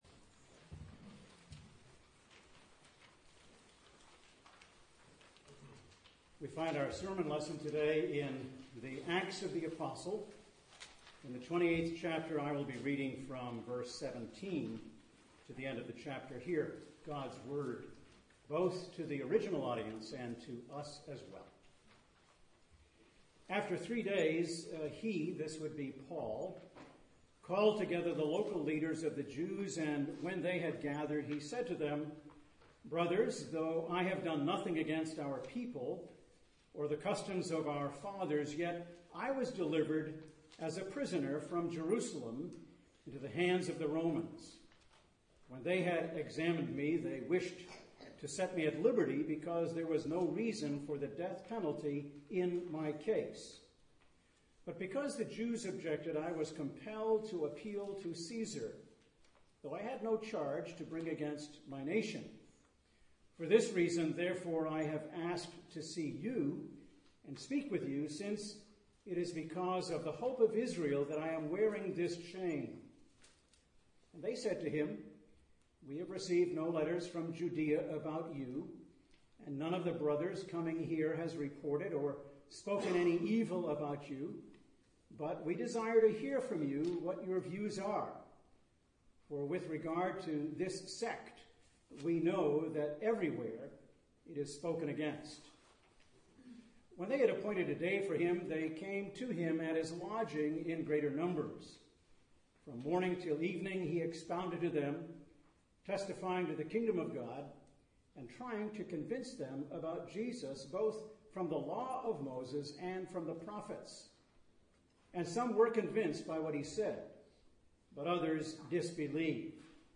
Passage: Acts 28:17-31 Service Type: Sunday Morning - Cedar Park « Church on the Move